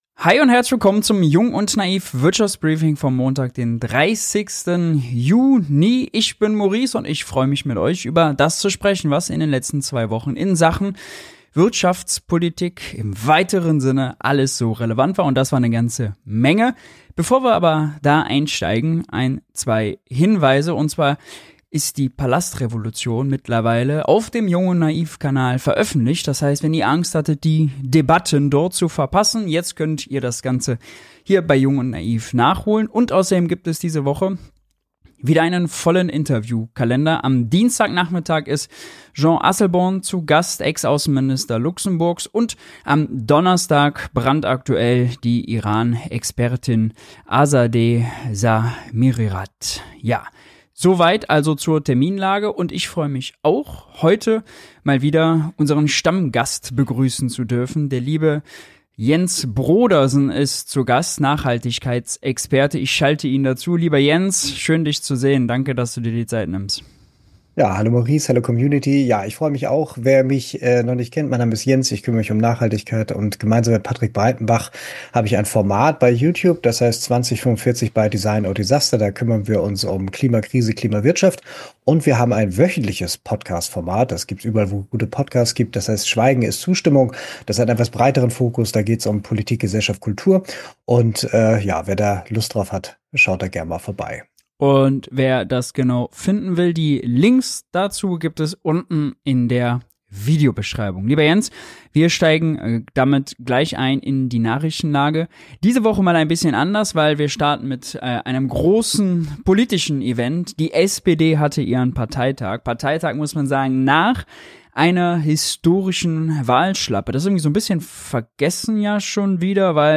Zu Gast: Nachhaltigkeitsexperte